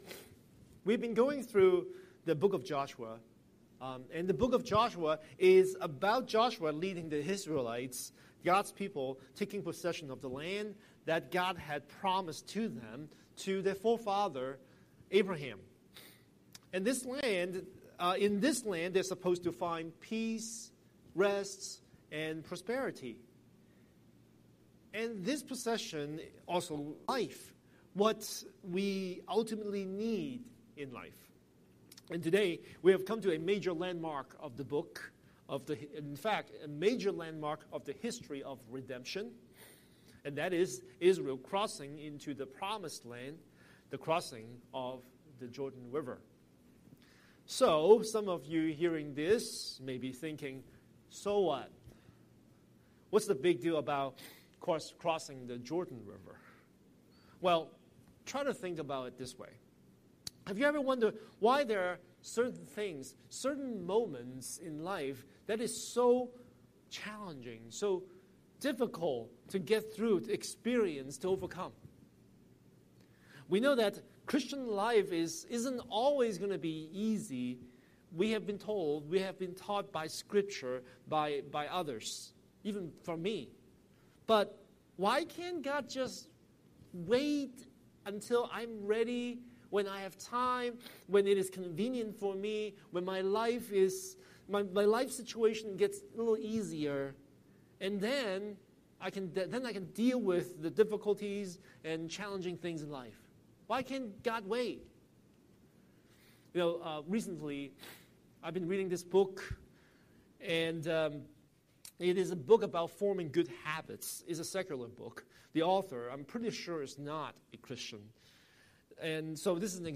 Scripture: Joshua 3:1–17 Series: Sunday Sermon